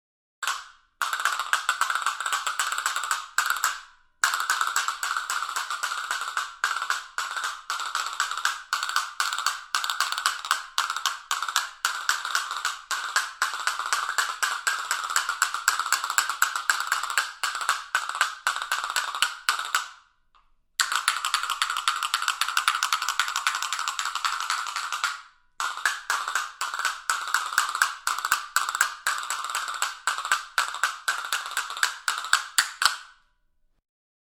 Castanets, five-point sound
• Category: Sound 5.1
• Quality: High